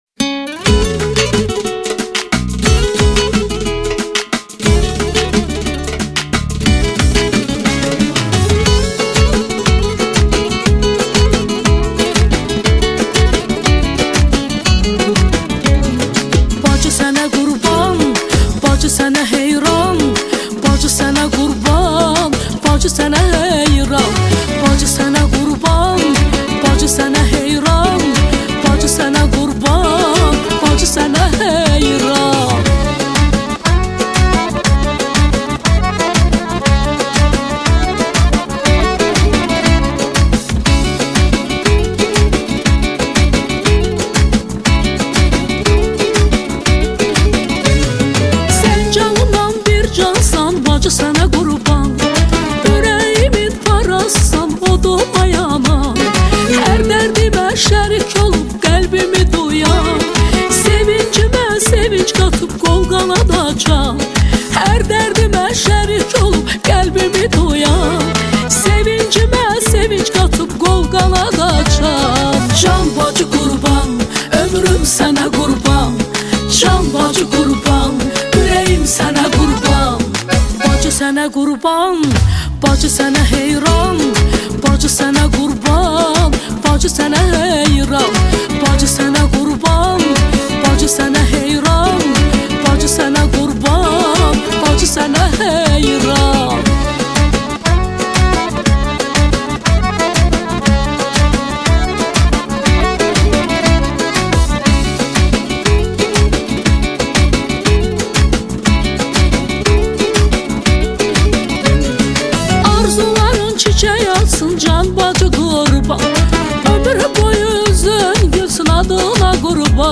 {بیس دار}